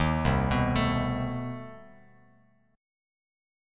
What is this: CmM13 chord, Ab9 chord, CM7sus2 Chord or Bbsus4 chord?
CmM13 chord